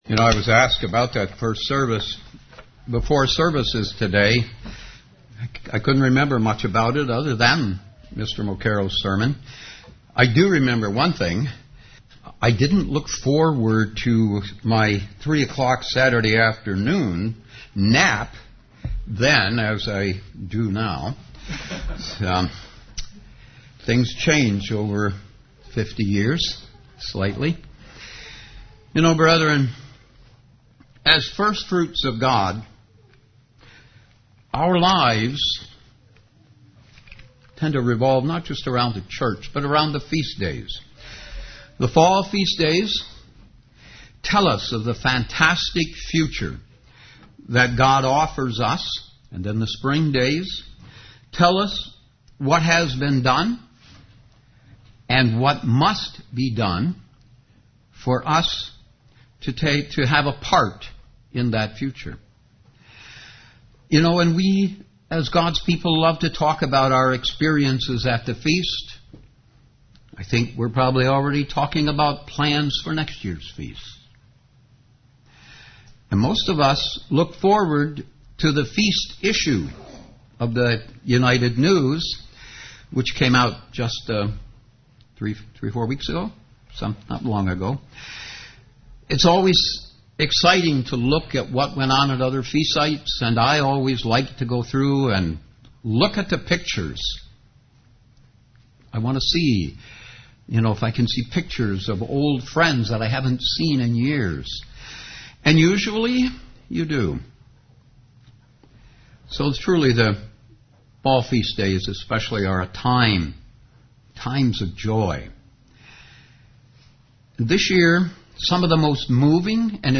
Sermons
Given in Ann Arbor, MI Detroit, MI